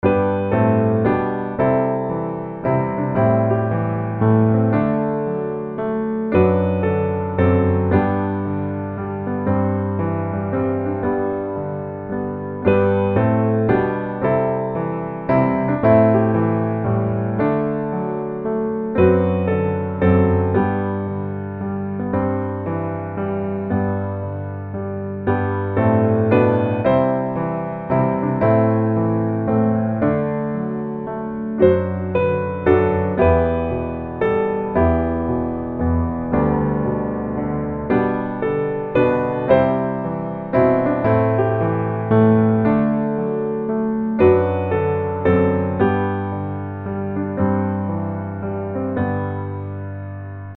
G大調